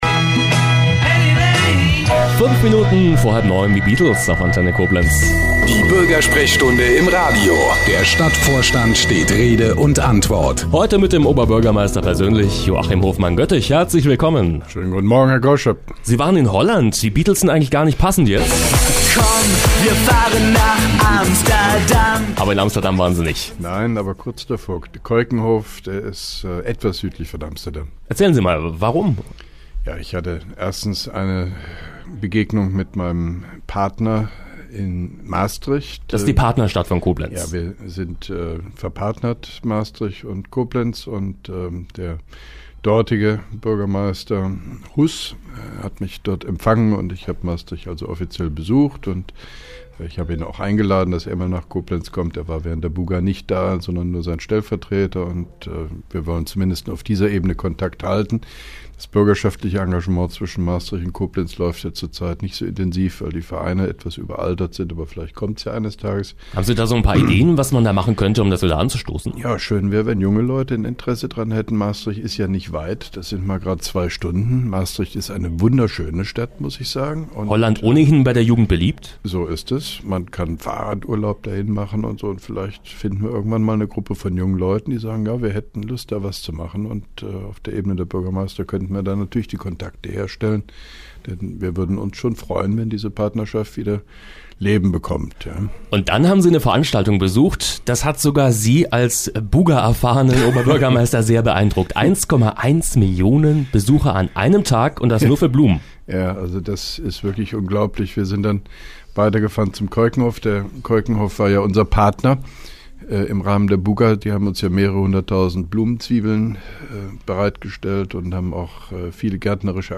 (1) Koblenzer Radio-Bürgersprechstunde mit OB Hofmann-Göttig 24.04.2012